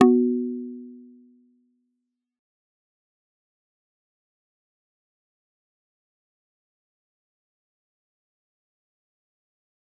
G_Kalimba-B3-f.wav